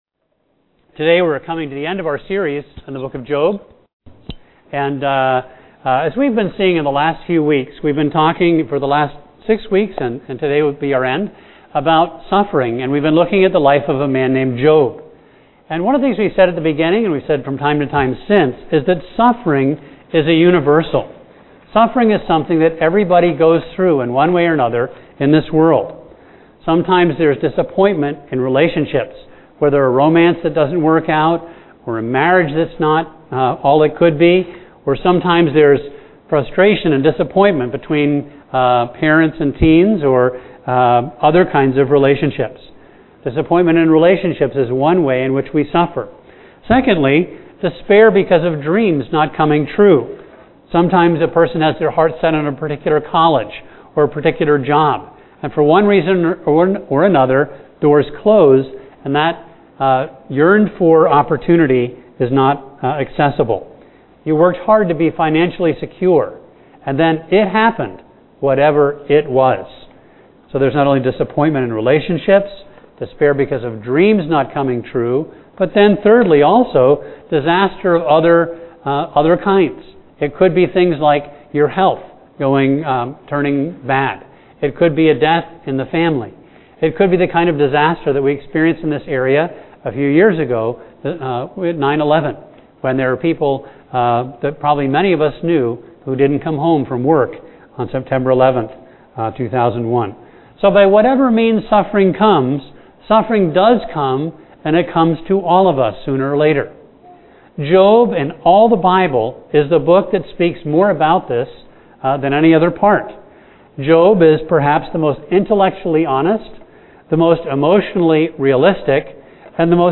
A message from the series "Job."